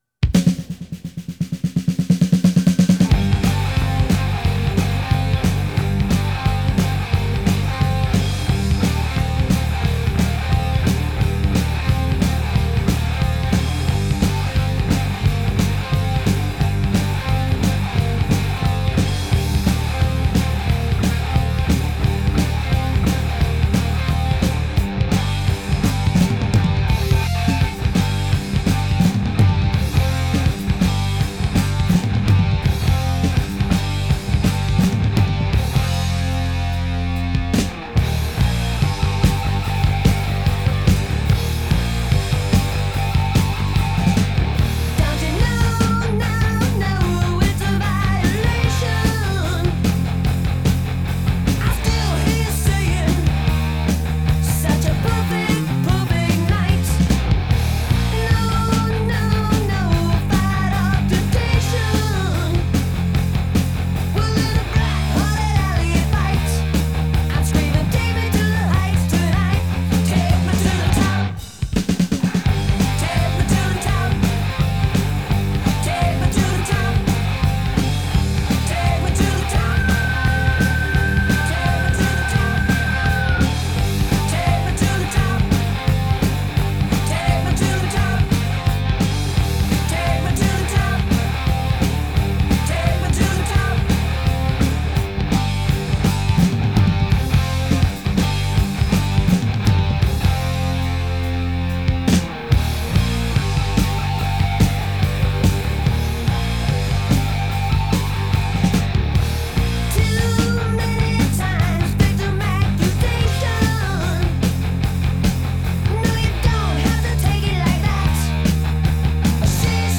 گلم متال